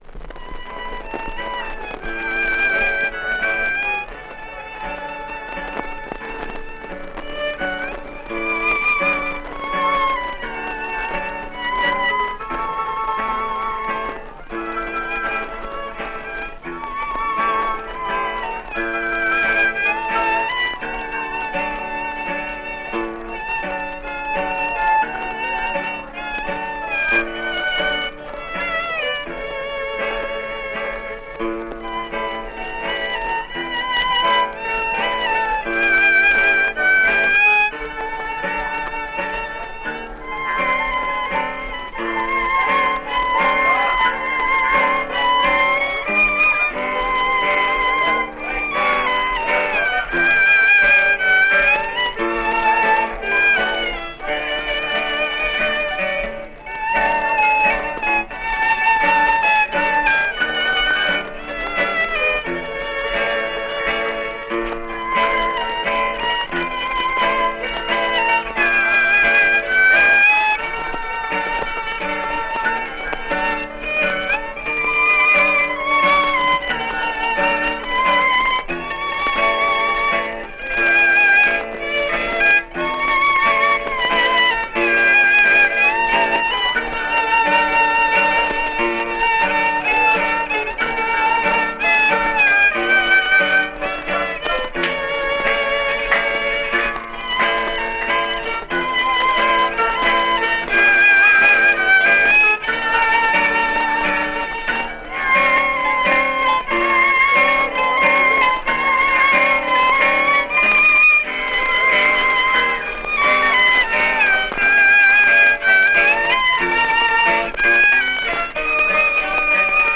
The music might be fast or slow but what they all have in common is a regular one-two-three, one-two-three rhythm.
There's also the Mexican waltz, which moves much faster. Listen to this Mexican waltz music and you can imagine how fast the dancers had to move to keep up with the music.
jp_dance_waltz_2.wav